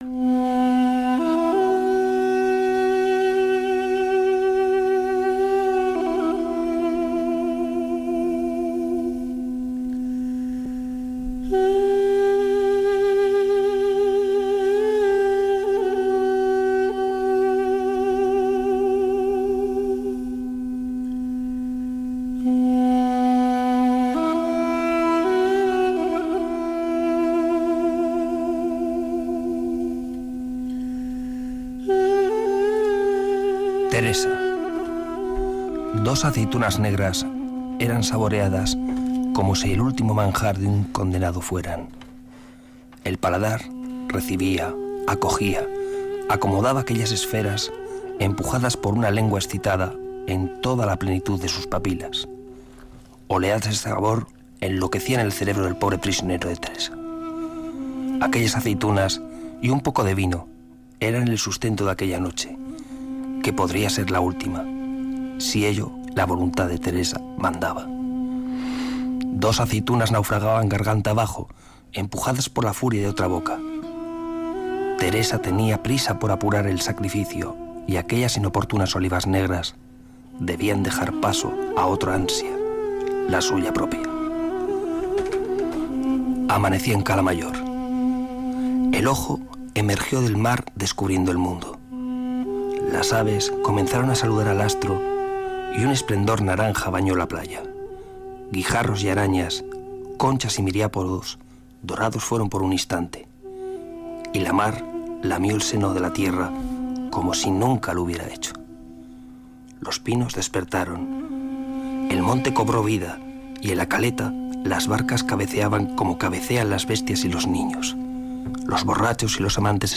Lectura del relato